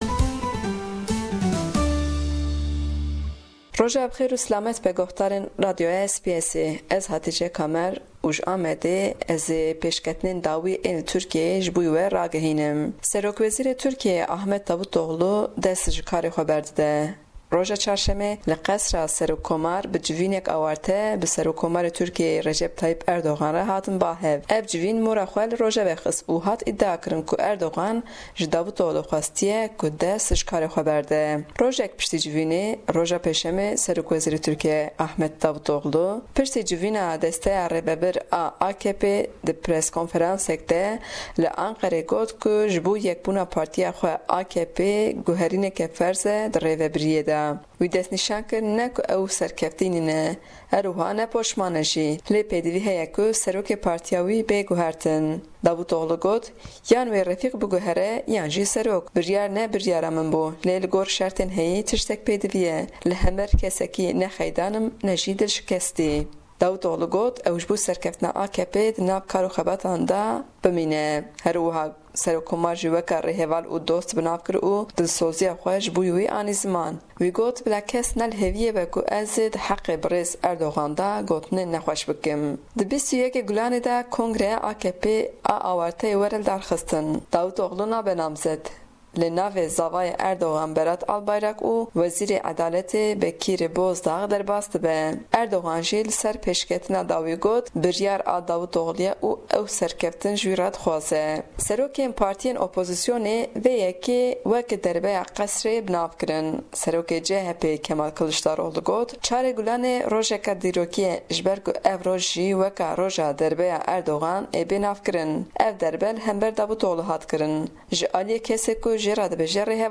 ji Diyarbekir nûçeyên herî dawîn sebaret bi pêkana istîfakirina SW Ahmet Davutoglu radigihîne.